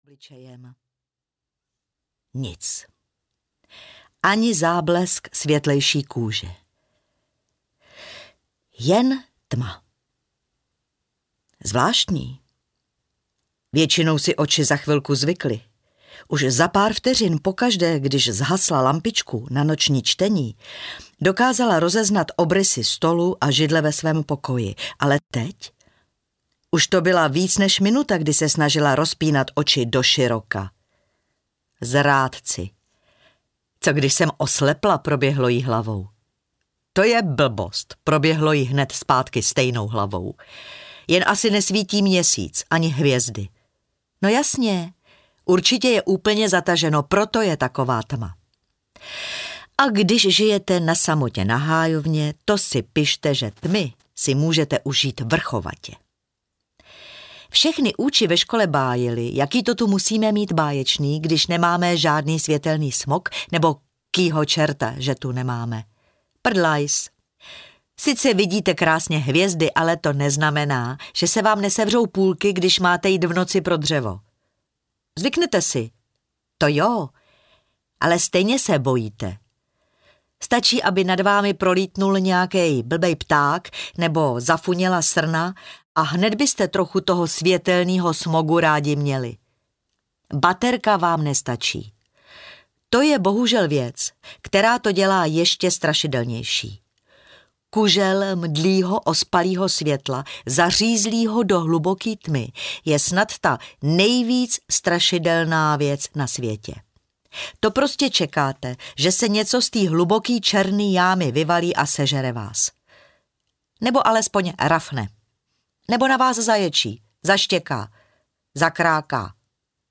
Morana Mařena audiokniha
Ukázka z knihy
Čte Milena Steinmasslová.
Nahrávka byla natočena ve studiu BaseCamp Studio.
• InterpretMilena Steinmasslová